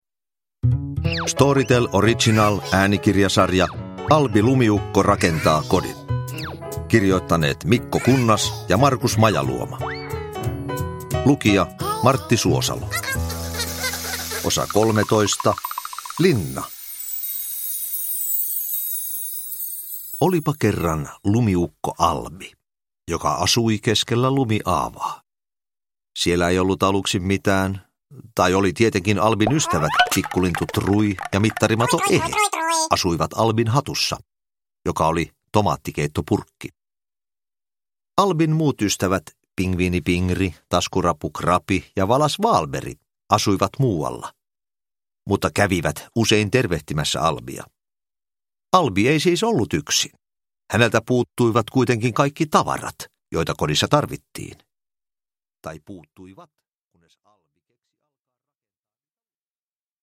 Uppläsare: Marrti Suosalo